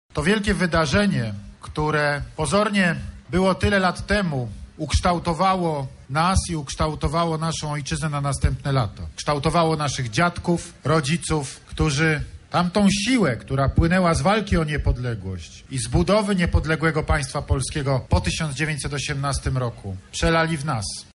Prezydent złożył kwiaty i wygłosił okolicznościowe przemówienie. Zaznaczył, jak wielką rolę odegrał 11 listopada w kreowaniu polskiej tożsamości: